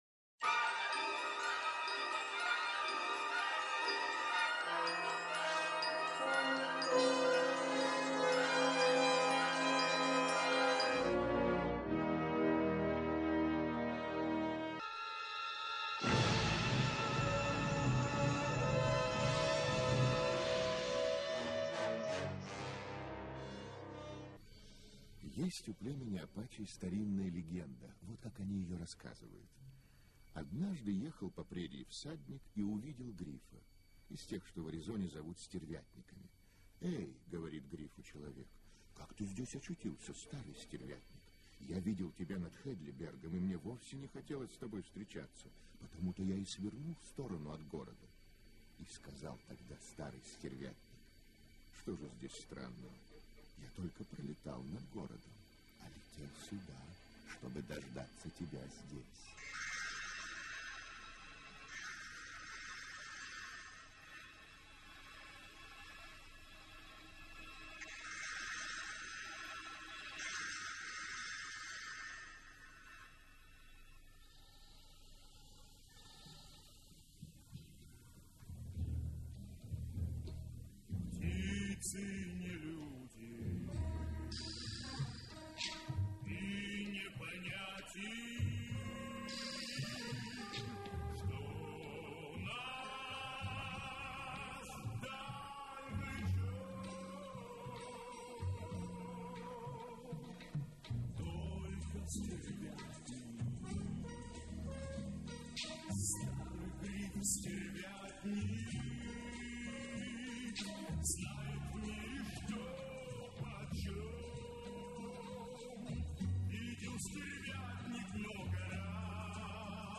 С дикторским текстом.